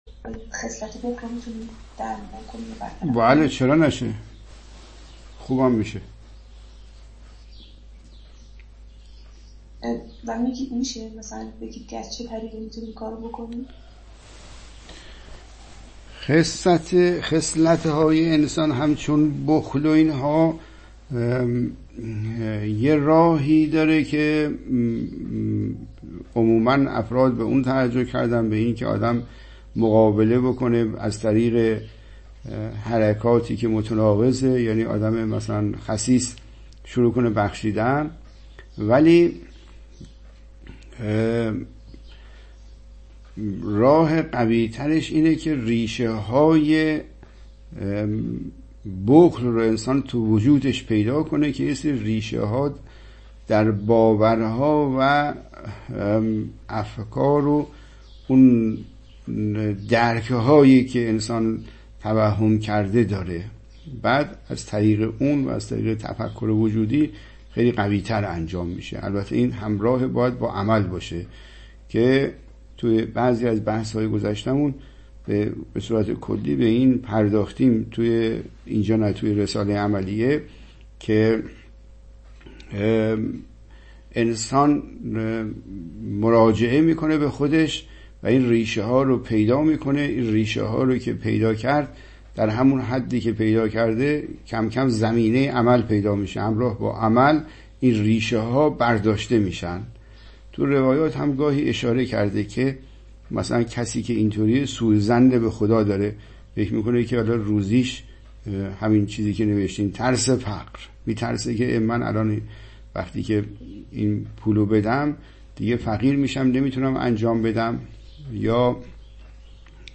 متن : گفت‌وگو